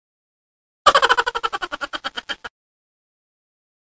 Laugh (Cartoon Style)
cartoon-laugh.mp3